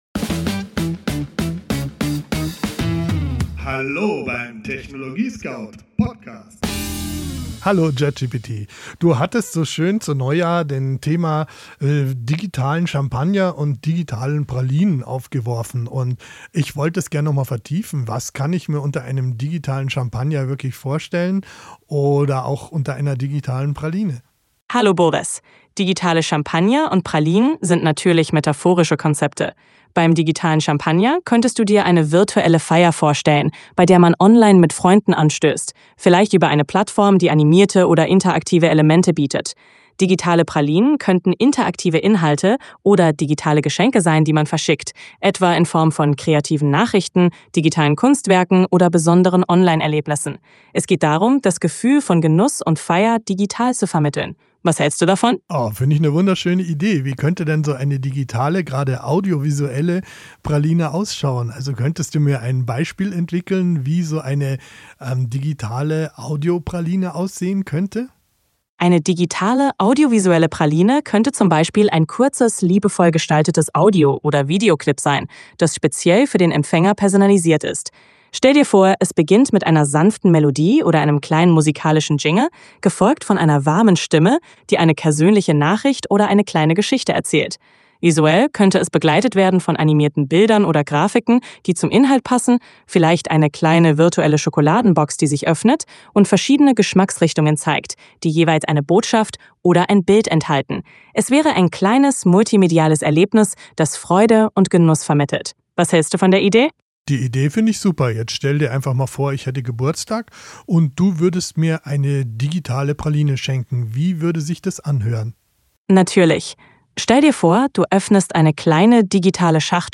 Dialog im TechnologieScout-Studio